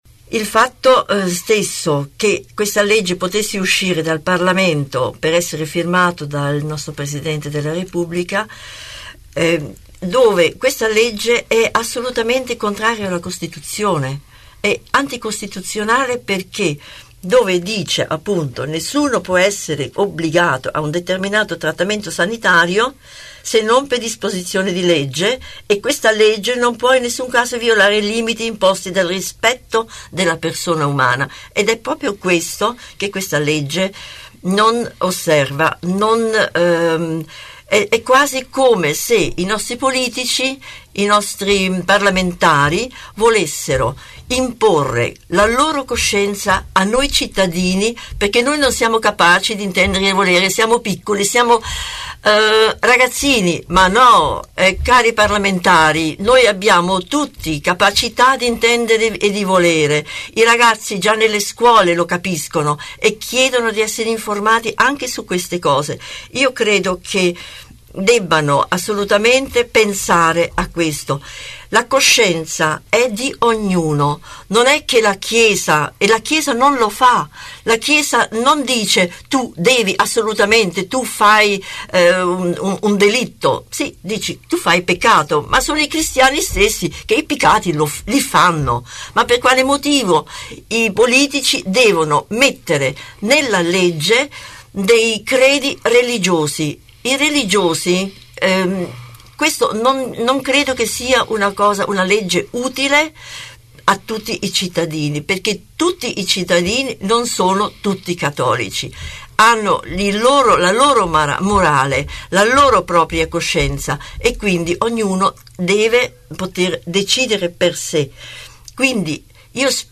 A bassa voce pronuncia parole ferme e decise. perché non serve alzare la voce per spiegare cosa sta accadendo, basta citare la Costituzione.